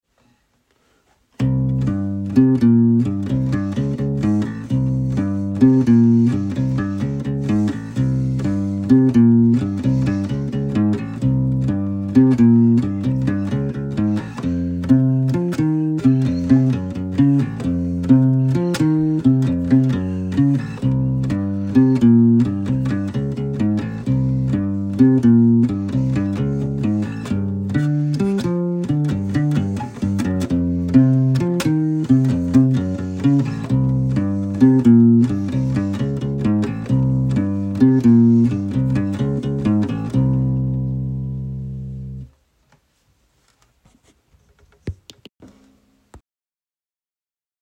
Bassriff-Quiz
Argh das kenn ich..is so ne krass Plastik mäßige Elektronummer Ende 70er